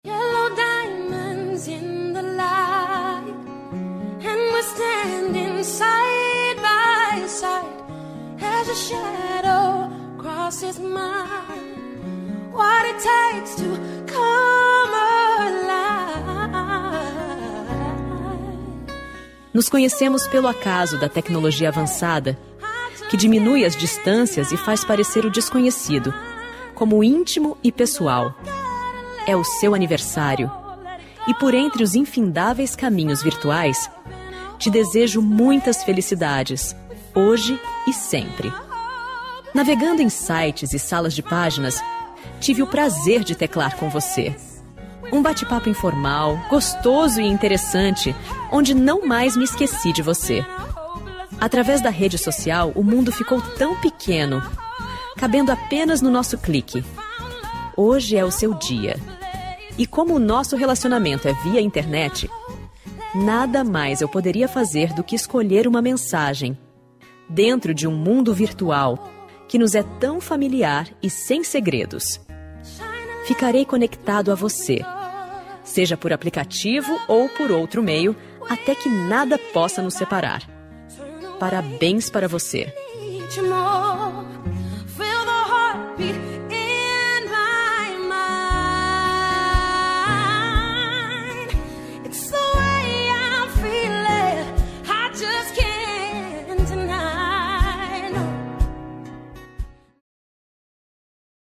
Aniversário Virtual Distante – Voz feminina – Cód:8887